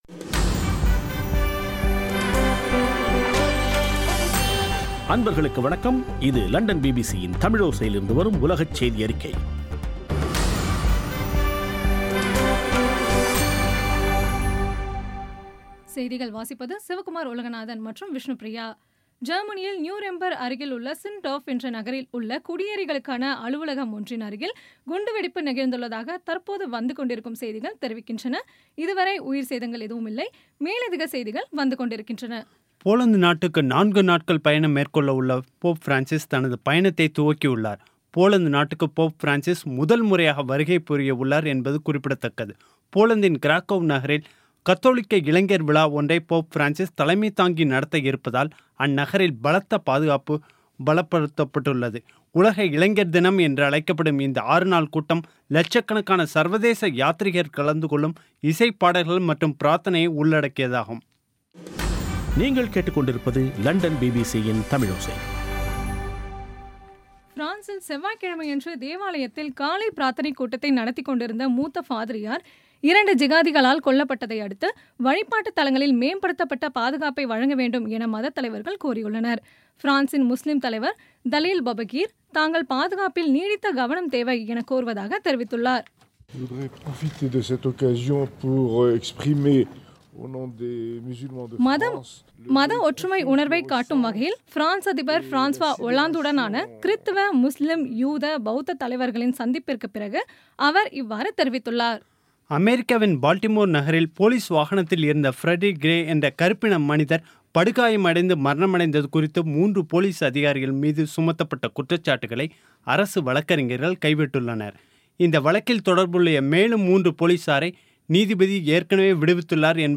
இன்றைய (ஜூலை 27ம் தேதி ) பிபிசி தமிழோசை செய்தியறிக்கை